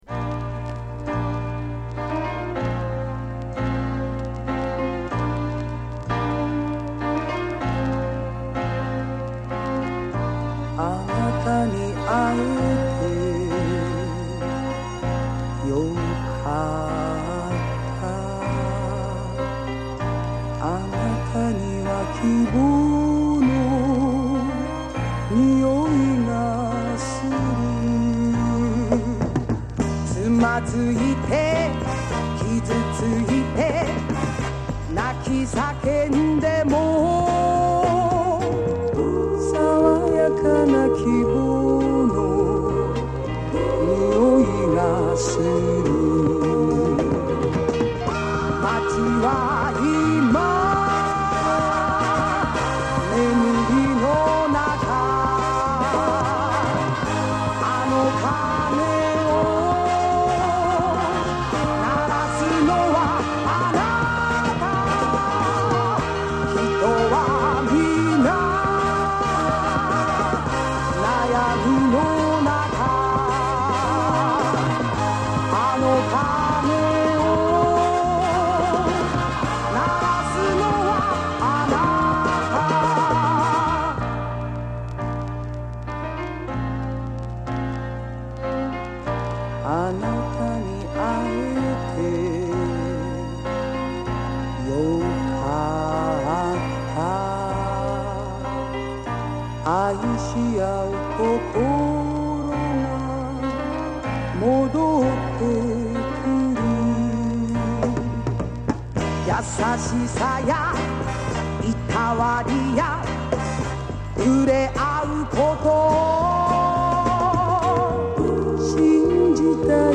盤に薄いスリキズ有/音の薄い部分で若干チリノイズ有
DJプレイ可能な和モノ作品の多い和製リズム・アンド・ブルーズ歌謡女王